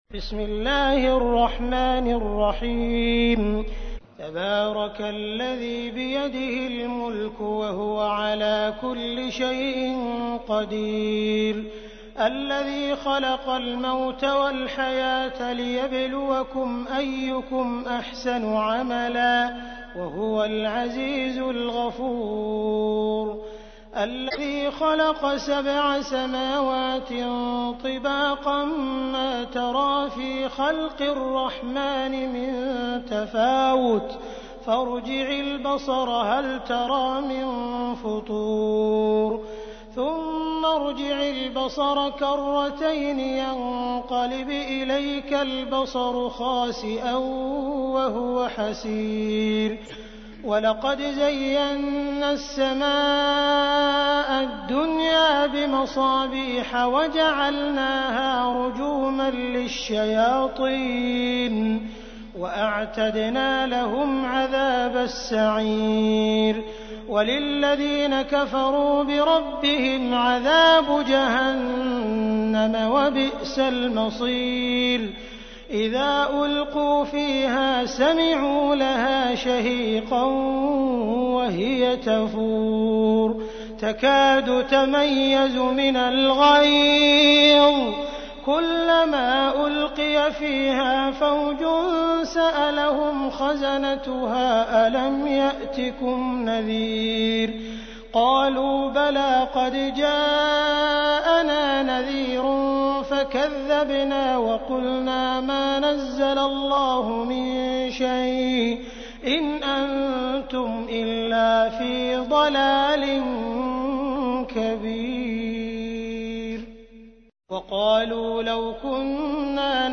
تحميل : 67. سورة الملك / القارئ عبد الرحمن السديس / القرآن الكريم / موقع يا حسين